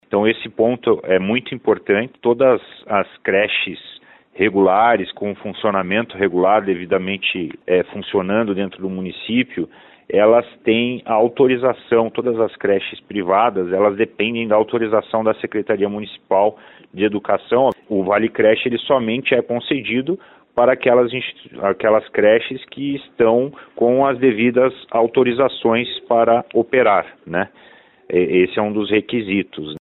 O secretário de educação de Curitiba, Jean Pierre Neto, ressalta que o valor é liberado a partir da comprovação da matrícula na creche e que a instituição precisa estar com todos os registros operacionais em dia com a prefeitura. Desde que esteja com as autorizações em dia, o beneficiário pode escolher em qual instituição particular matricular a criança.